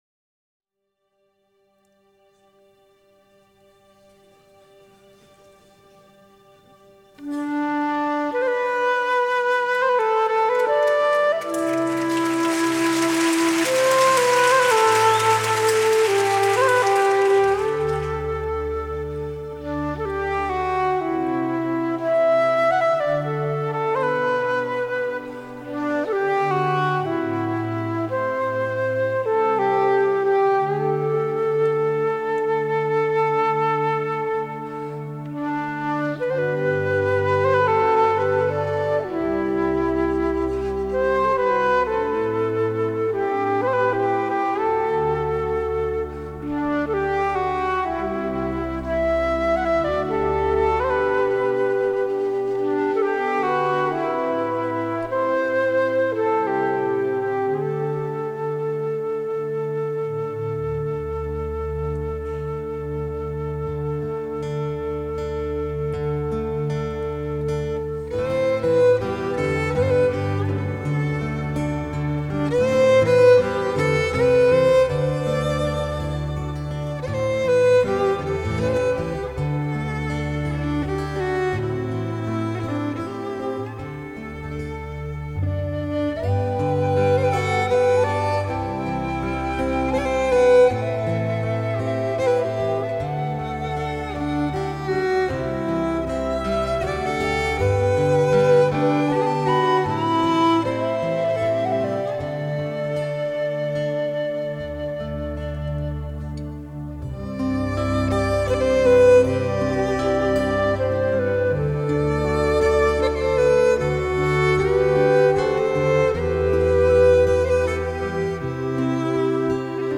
新品/爵士/世界音乐
主奏乐器：人声、民族乐器
难得一闻在西班牙的现场演出版，